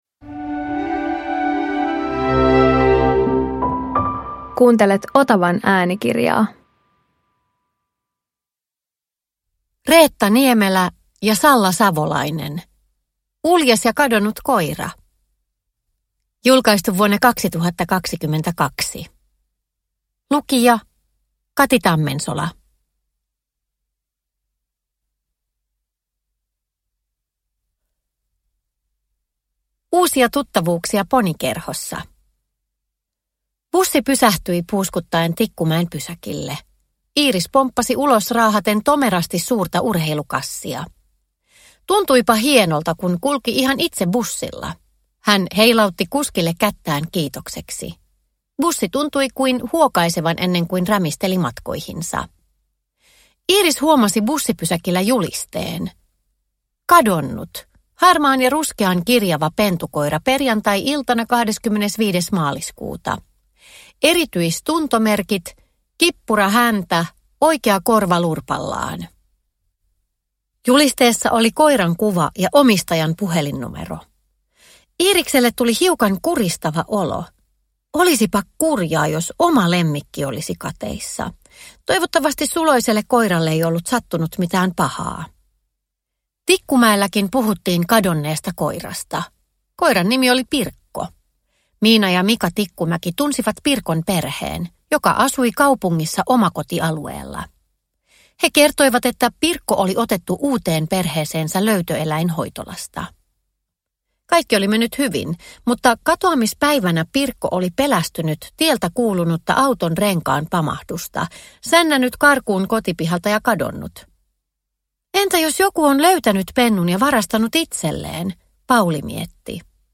Uljas ja kadonnut koira – Ljudbok